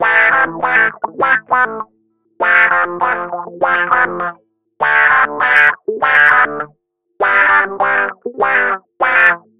四和弦吉他华
描述：电吉他
Tag: 100 bpm Pop Loops Guitar Electric Loops 828.45 KB wav Key : A